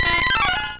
Cri de Férosinge dans Pokémon Rouge et Bleu.